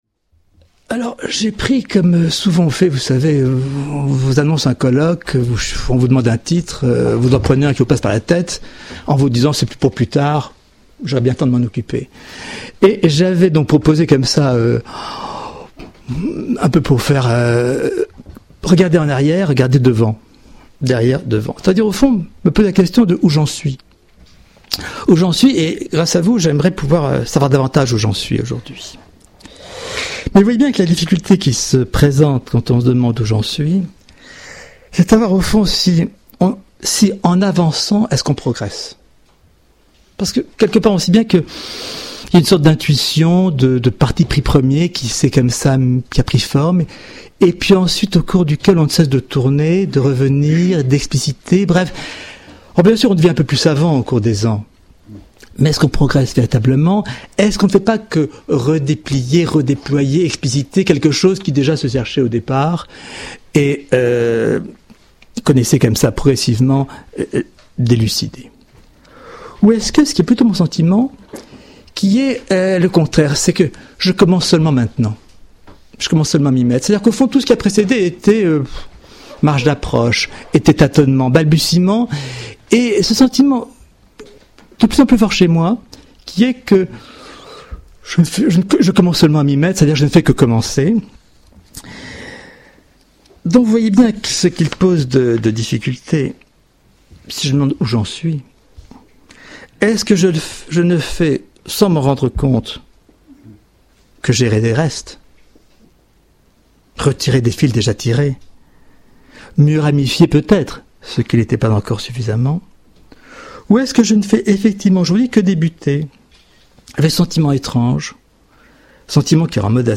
Cette conférence a été prononcée dans le cadre du colloque intitulé Des possibles de la pensée.